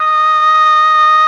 RED.OBOE  33.wav